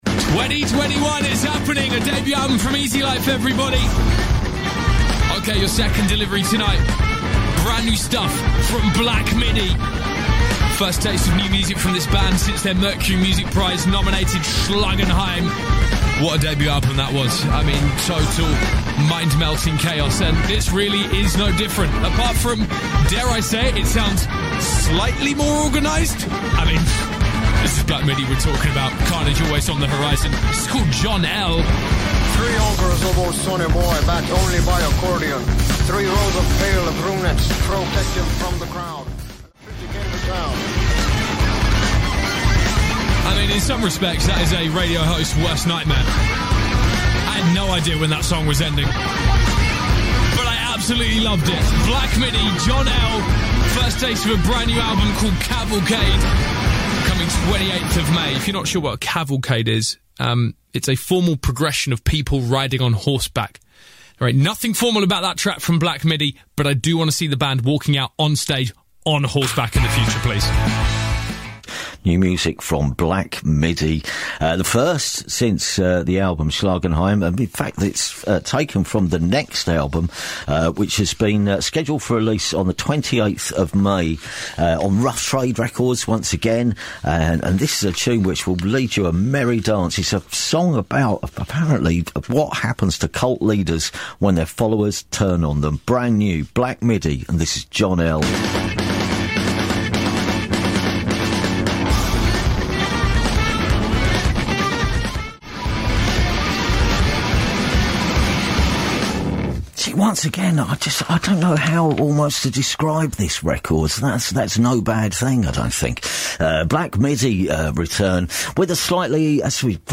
BBC Radio 1 Jack Saunders／Radio 6 Steve Lamacqの発音